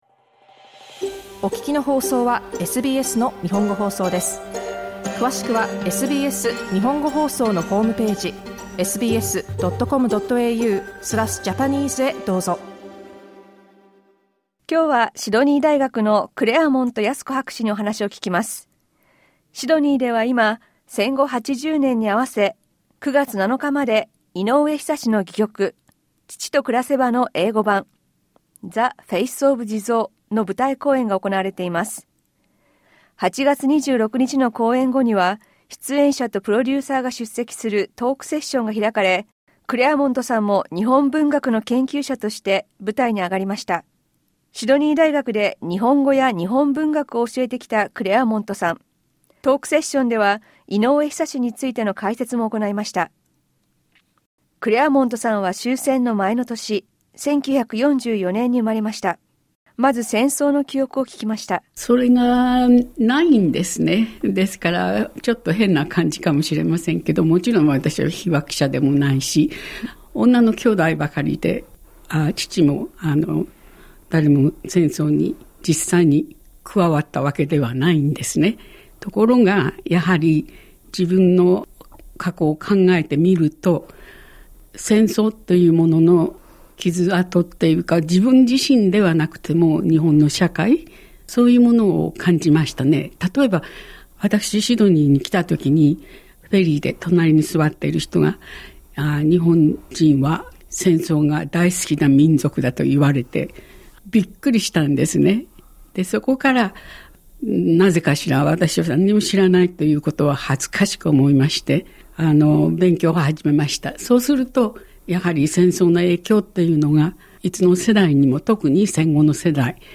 インタビューでは、戦争について、オーストラリアでの経験について、そして11月のイベントなどについて聞きました。